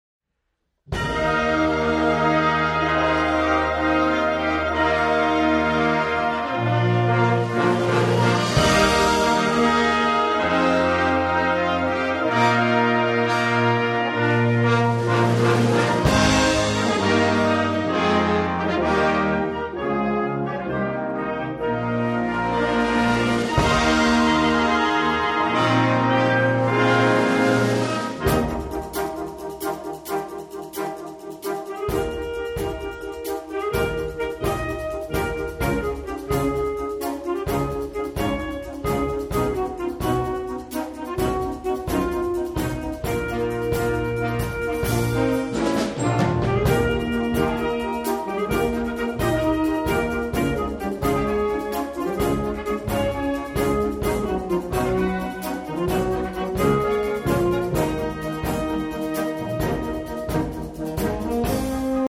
Wind Band - A good overture is like an aperitif at a dinner.